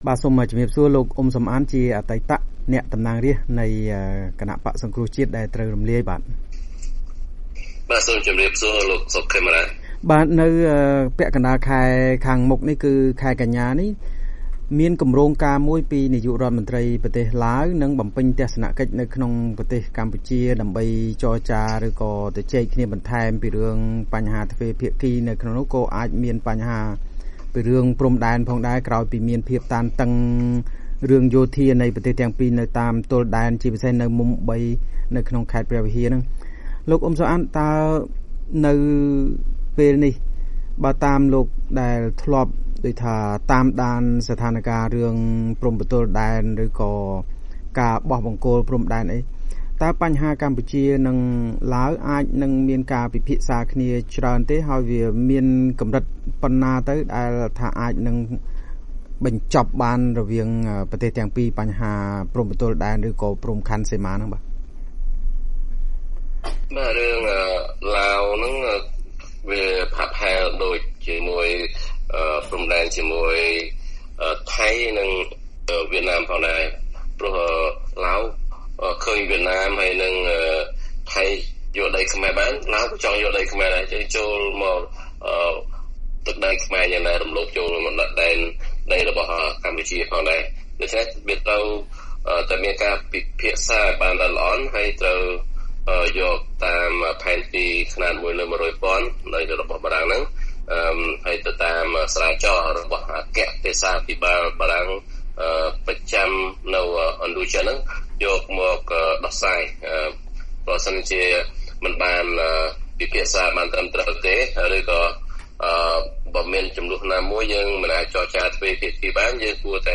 បទសម្ភាសន៍ VOA៖ អ្នកជំនាញថាវិវាទព្រំដែនជាមួយឡាវត្រូវនាំទៅតុលាការអន្តរជាតិបើគ្មានដំណោះស្រាយសមស្រប